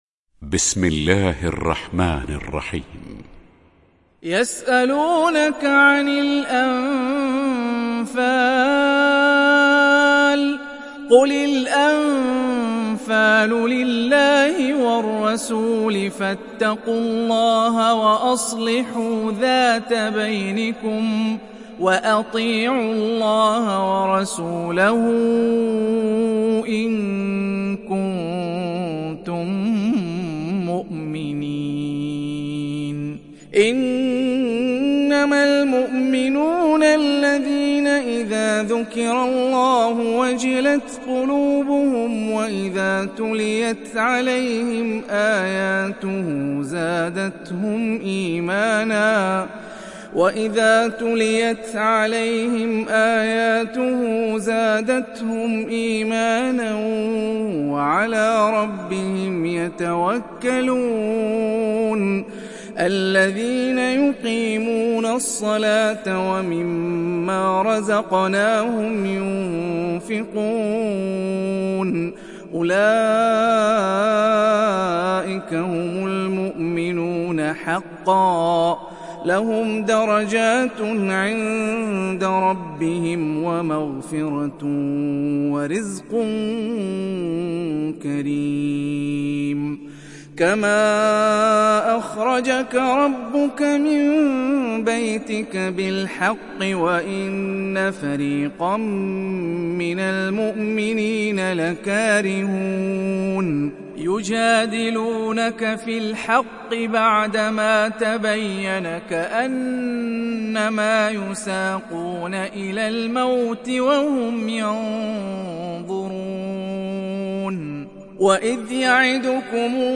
تحميل سورة الأنفال mp3 هاني الرفاعي (رواية حفص)